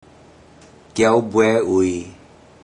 Click each Romanised Teochew word to listen to how the Teochew word is pronounced.
kiao42bue42ui0.mp3